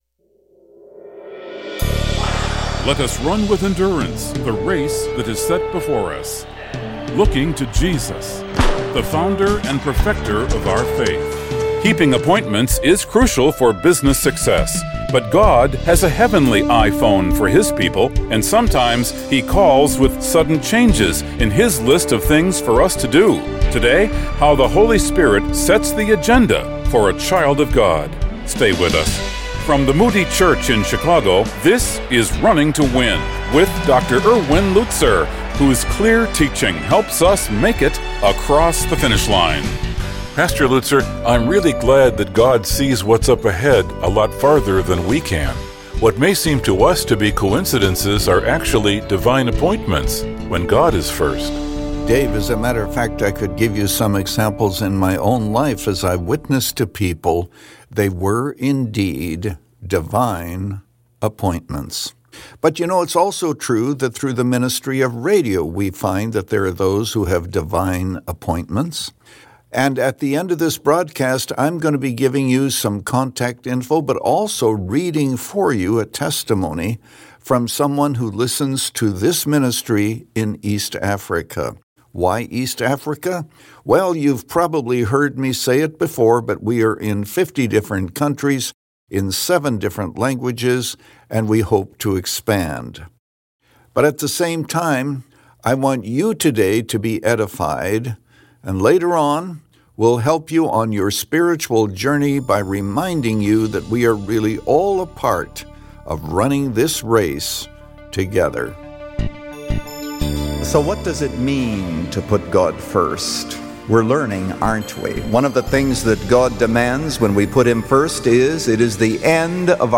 God prepares the hearts of men and women from every nation to receive the Gospel. God worked through Philip, and an Ethiopian official became a follower of Jesus. In this message from Acts 8